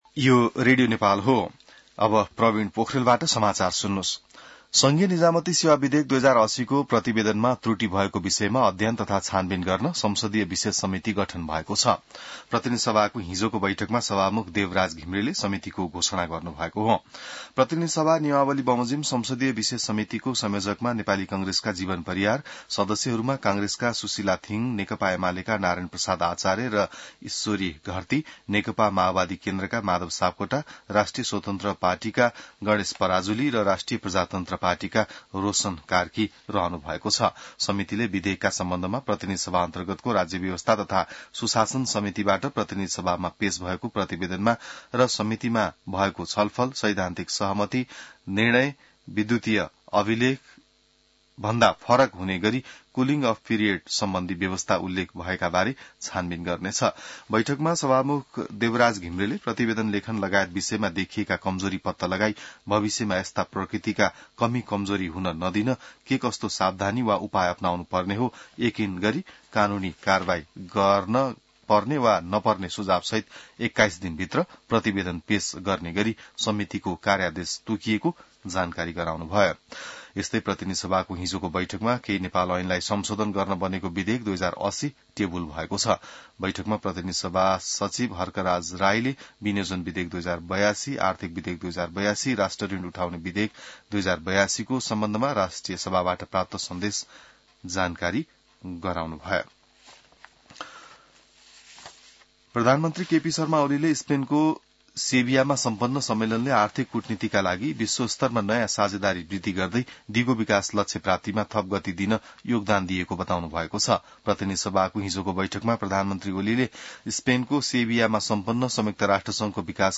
बिहान ६ बजेको नेपाली समाचार : २४ असार , २०८२